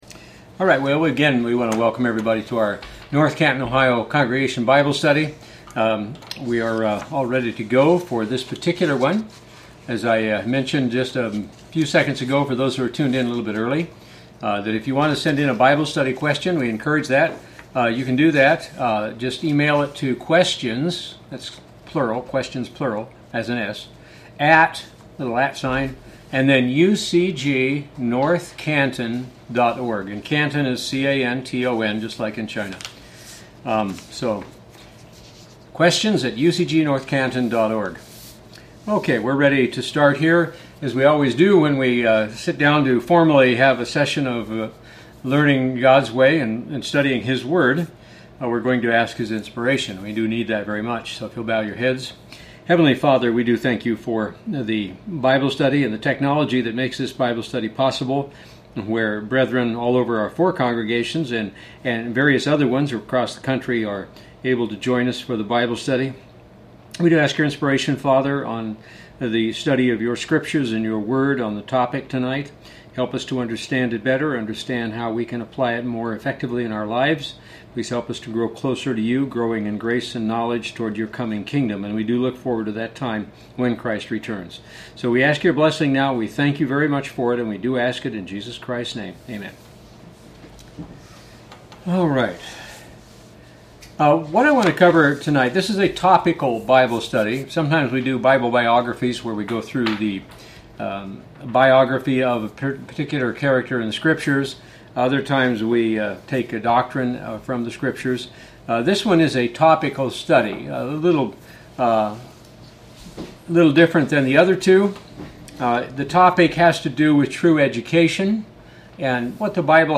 In tonight's Bible Study we'll look at four levels of learning which are also four levels of teaching.